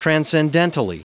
Prononciation du mot transcendentally en anglais (fichier audio)
Prononciation du mot : transcendentally